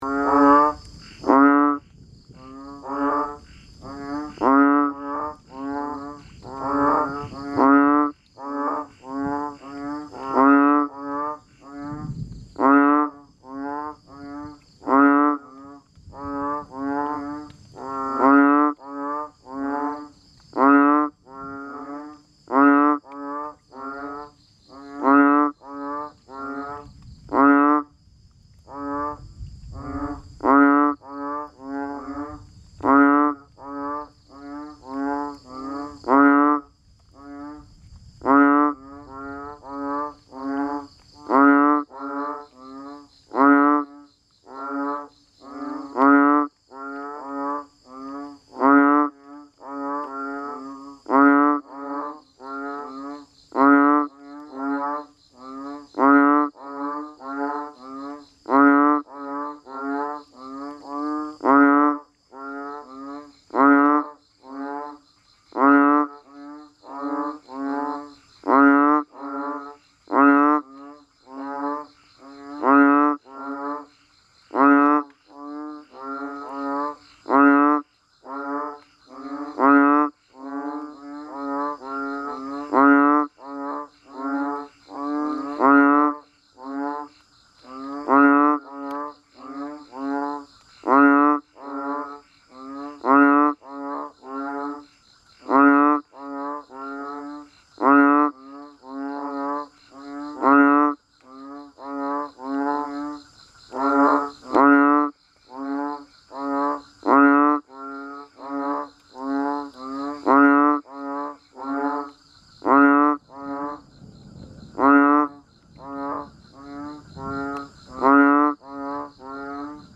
เสียงฝนตก เสียงกบ อึ่งอ่าง ธรรมชาติหลังฝนตก
หมวดหมู่: เสียงสัตว์ป่า
tieng-mua-roi-tieng-ech-nhai-am-thanh-tu-nhien-sau-con-mua-th-www_tiengdong_com.mp3